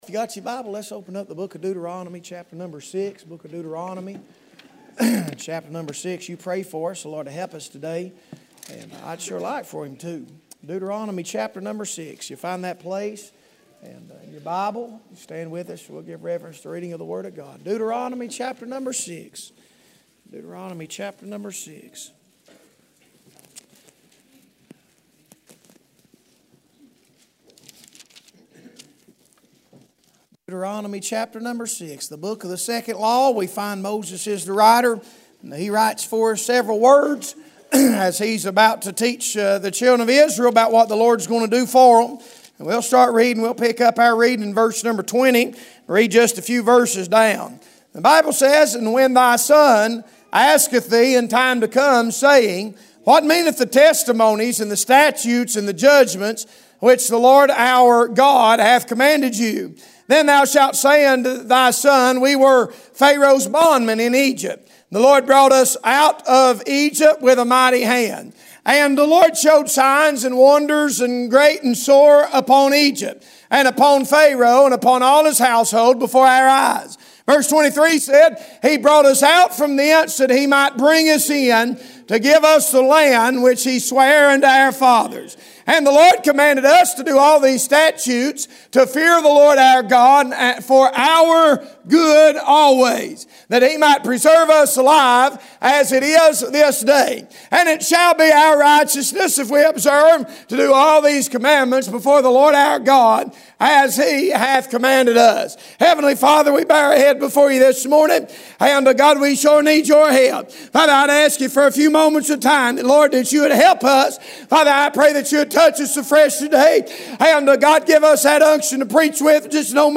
Passage: Deuteronomy 6:20-25 Service Type: Sunday Morning